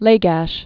(lāgăsh)